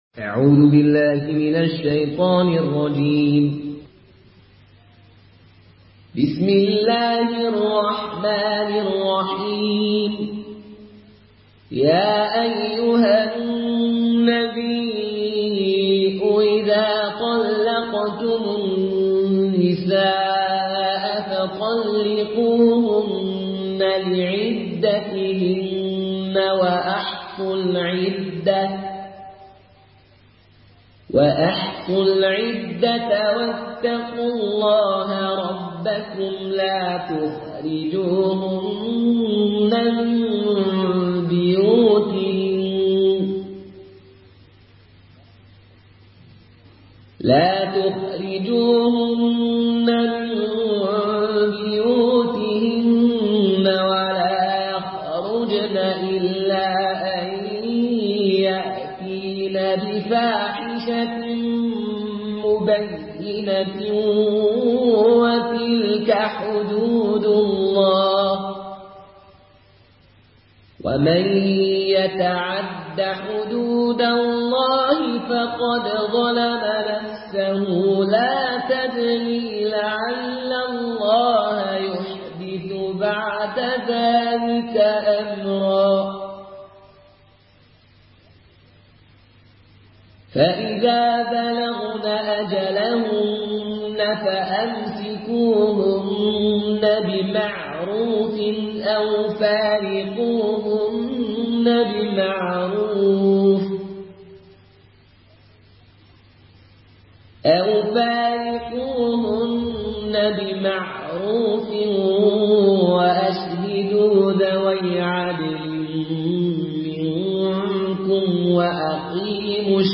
برواية قالون
مرتل